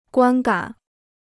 观感 (guān gǎn) Free Chinese Dictionary